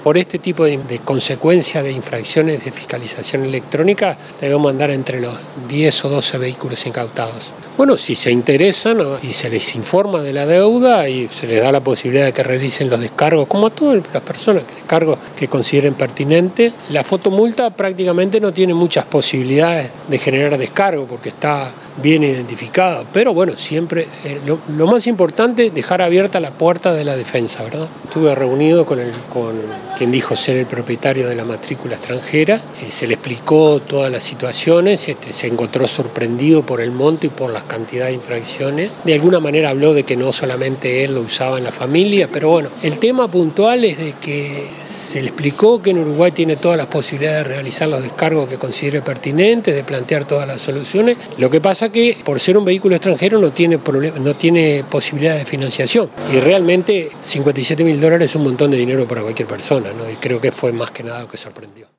Escuchar la entrevista al director de Tránsito de la Intendencia de Maldonado, Juan Pígola: